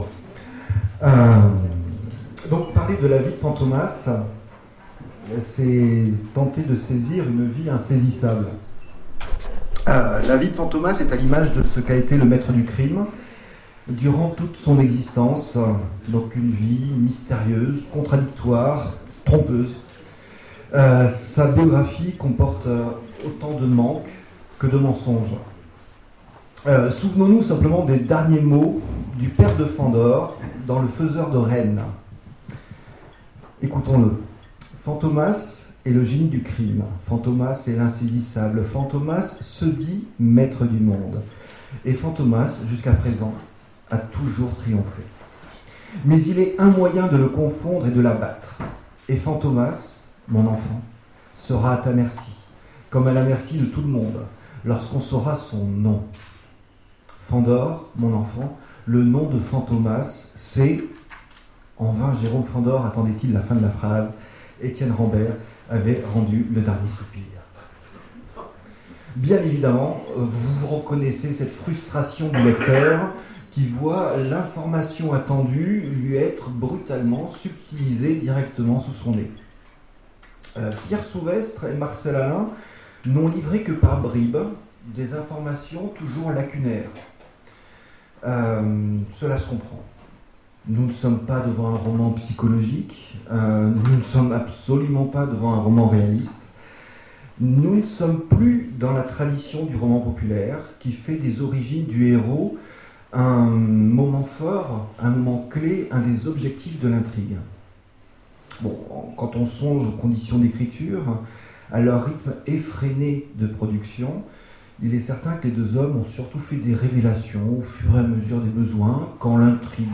Conférence Vie de Fantômas
Conférence